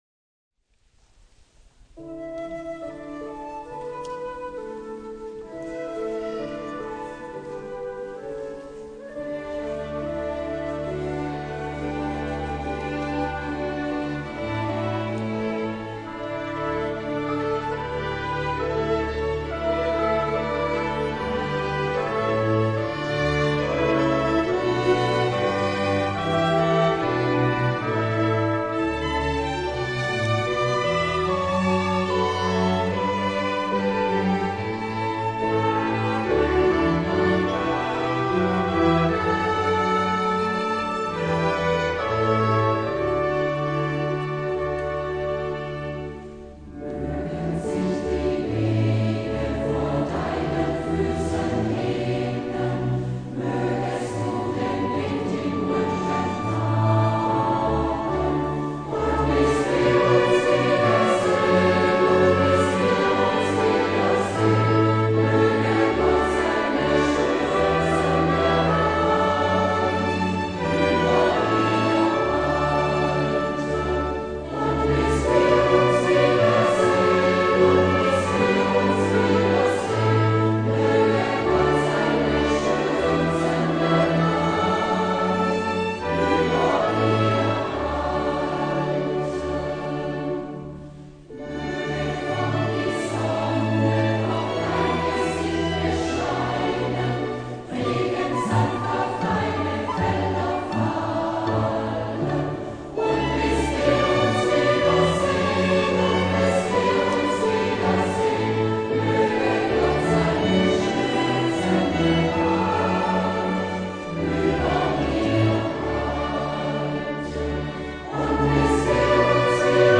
Orchesterbegleitsatz zu “Mögen sich die Wege vor deinen Füßen ebnen…”
Mögen sich die Wege vor deinen Füßen ebnen, Mühlenbach 24.10.2010